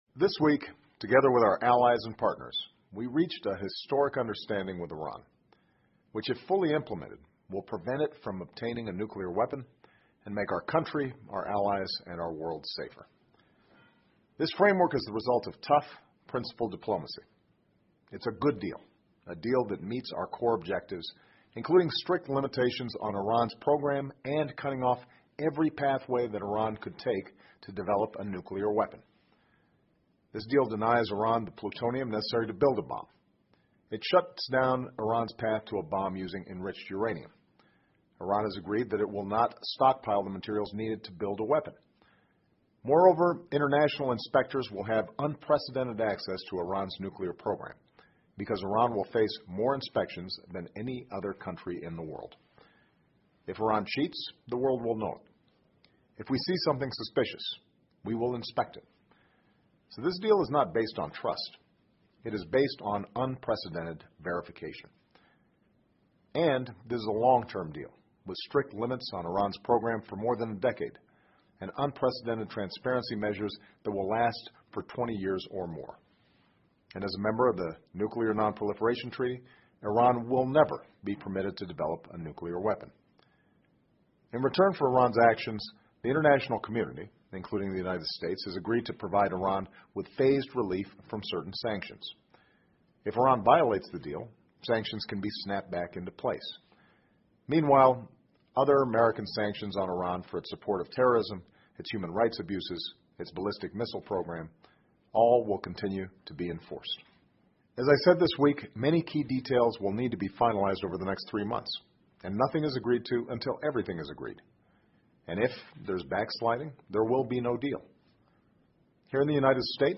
奥巴马每周电视讲话：总统呼吁促成全面伊朗核协议计划 听力文件下载—在线英语听力室